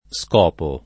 scopo [ S k 0 po ]